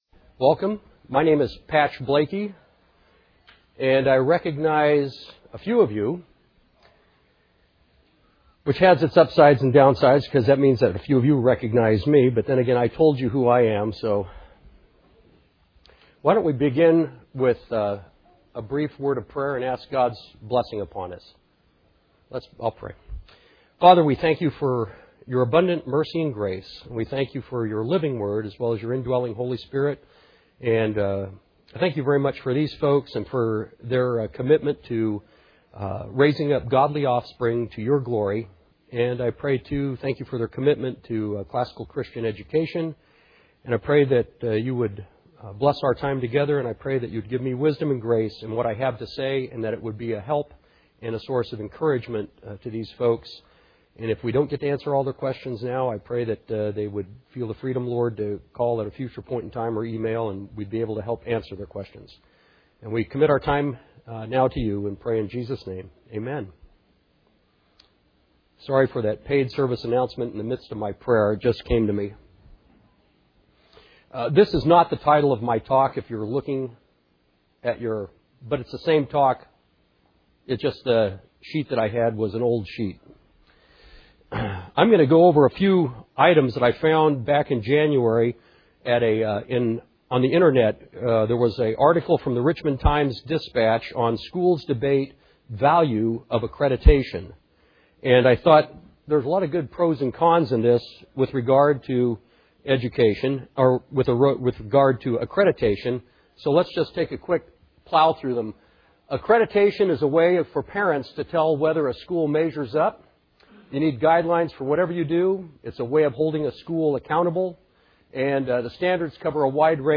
2008 Workshop Talk | 1:12:22 | Leadership & Strategic